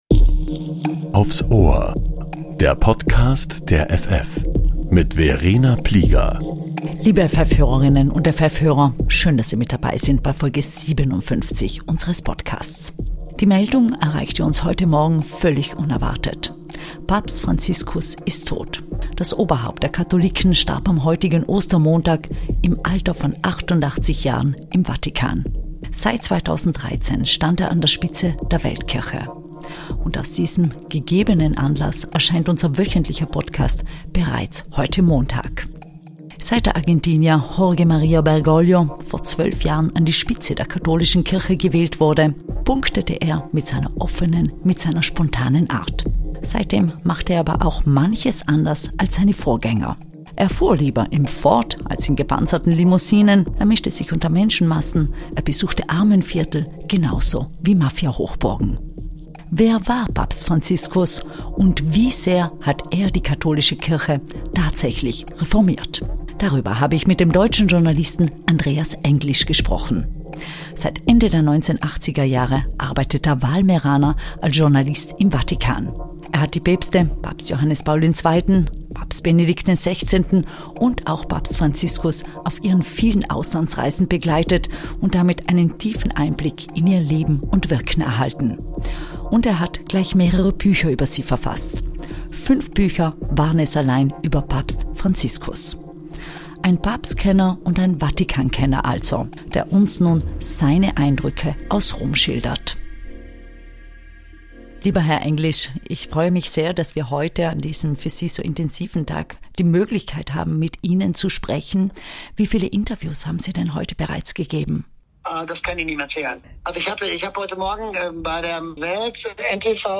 Der Papst-Kenner und Vatikan-Experte Andreas Englisch spricht über den Tod von Papst Franziskus